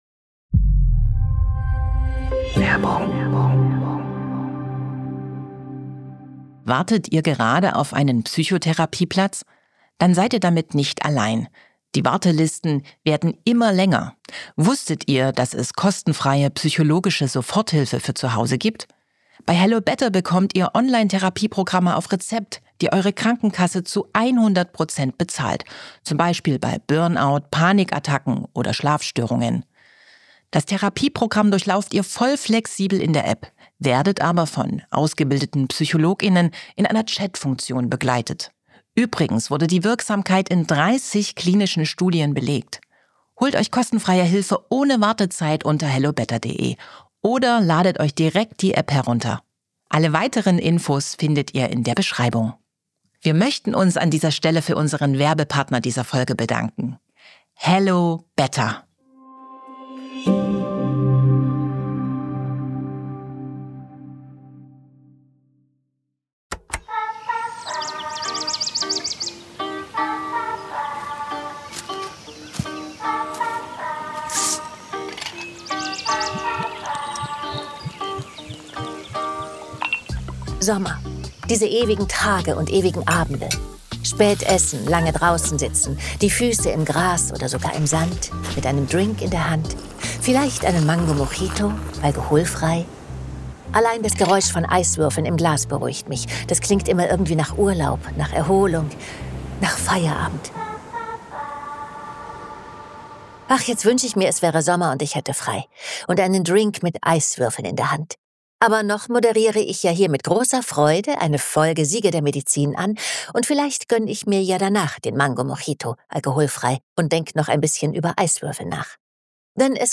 Schneller Flüssigkeitsverlust, bläulich graue Haut, Bewusstseinstrübung und Kreislaufversagen: wie eine der gefährlichsten Krankheiten bekämpft wurde und wird, erzählt Andrea Sawatzki in dieser Folge.